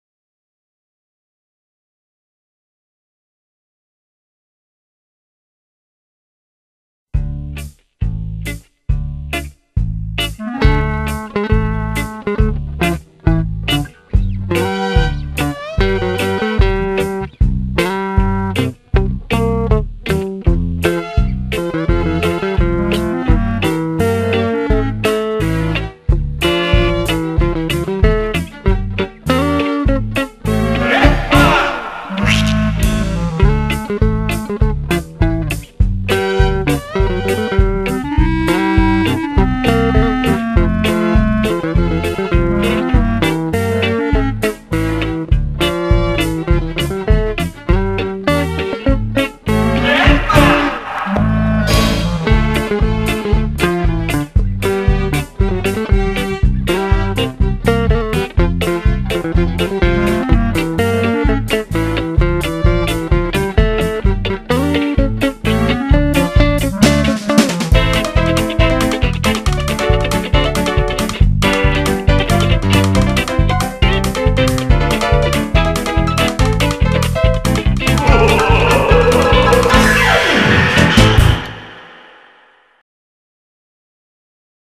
минусовка версия 8467